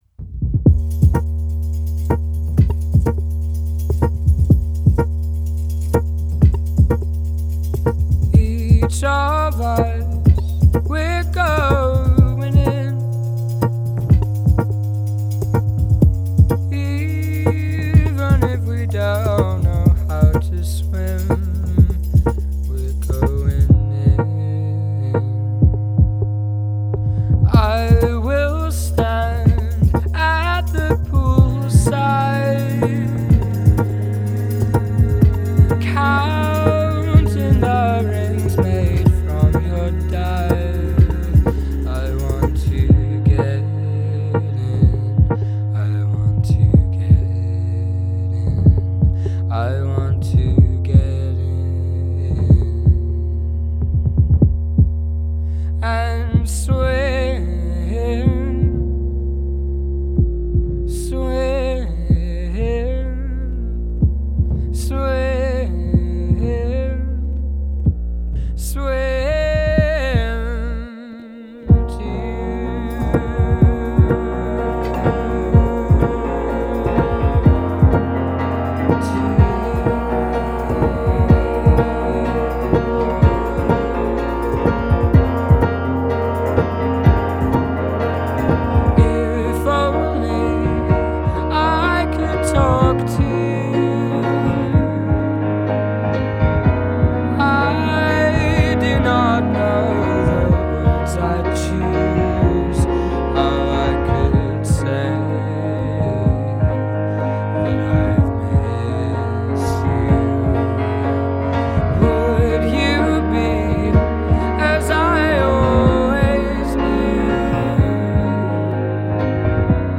Жанр: Indie.